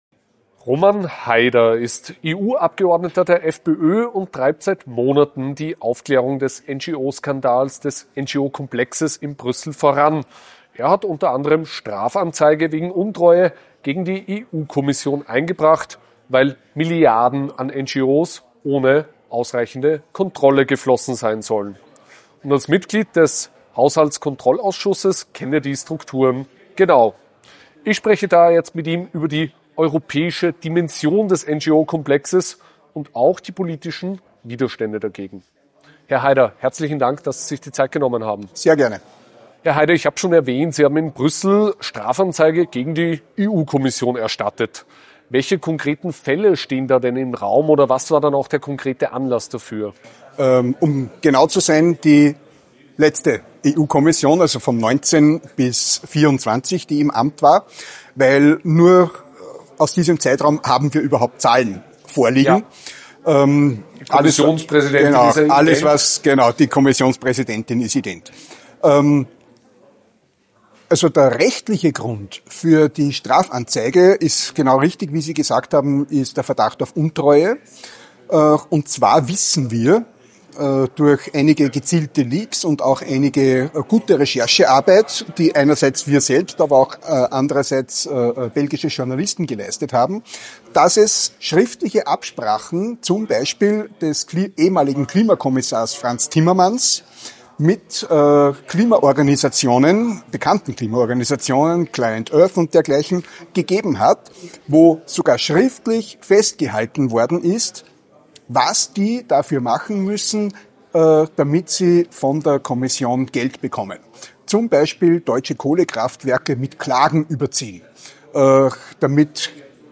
Im Gespräch mit AUF1 schildert er den Stand der Ermittlungen und erklärt, warum eine vollständige Aufklärung des Brüsseler NGO-Komplexes dringend notwendig ist.